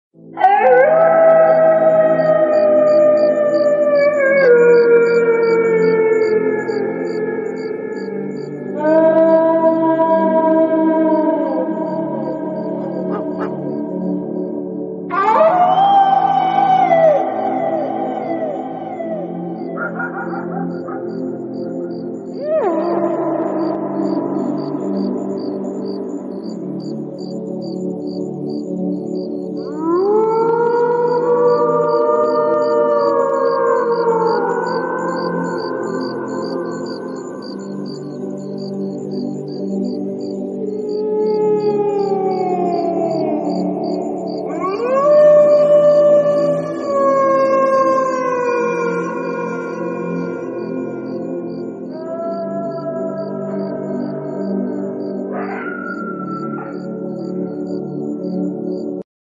🗣 Kenikmatan Suara Serigala Di sound effects free download
🗣 Kenikmatan Suara Serigala Di Malam Hari